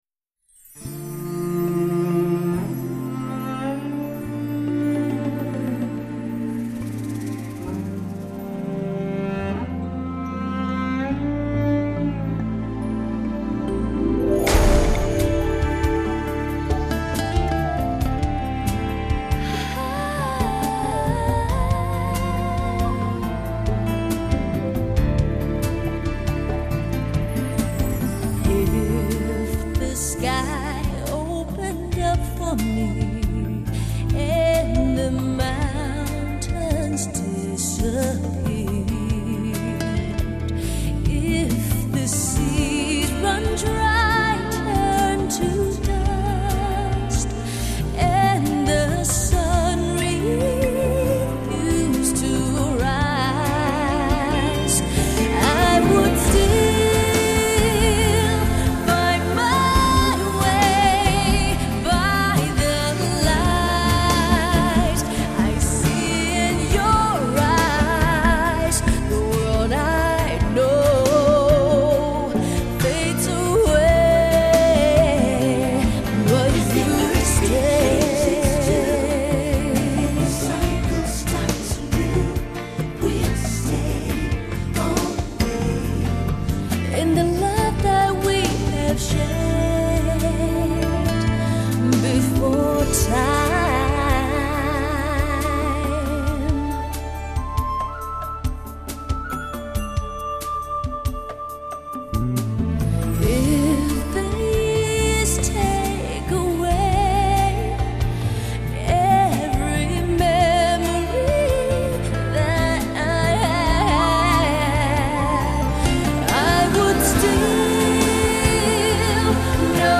Çello